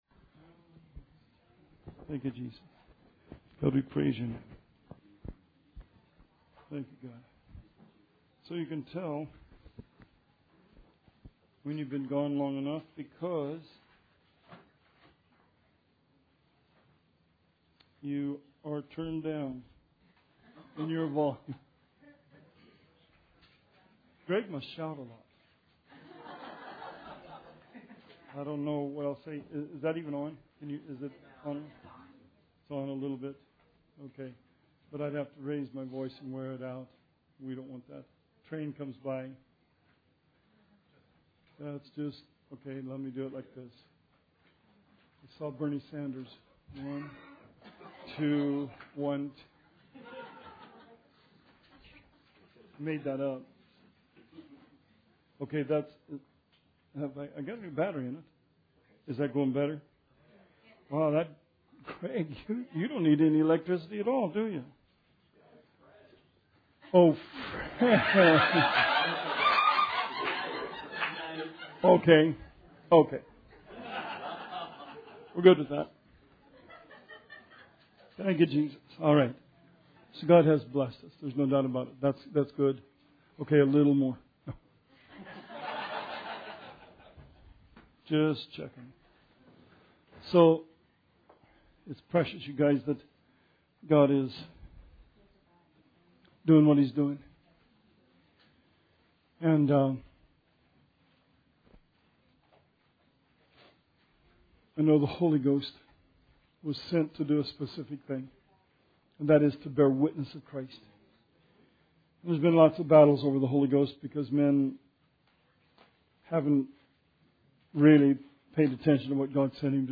Bible Study 7/27/16